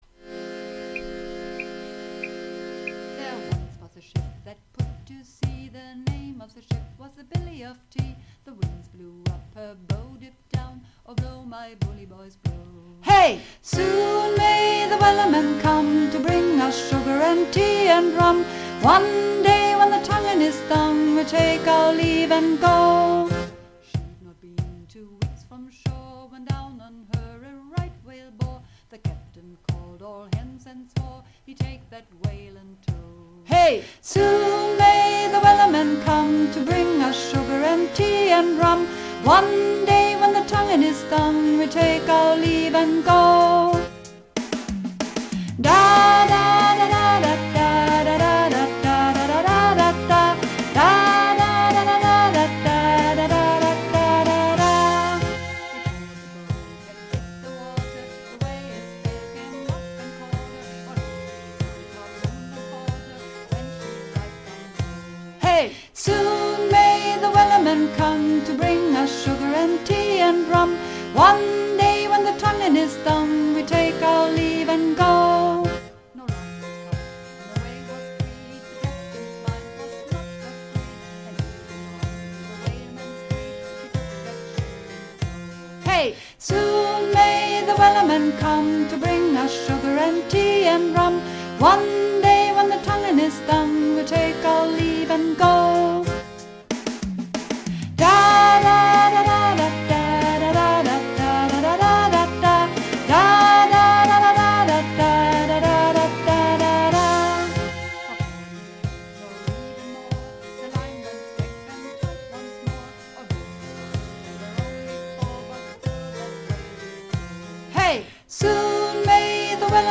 Übungsaufnahmen - Wellerman
Wellerman (Sopran)
Wellerman__3_Sopran.mp3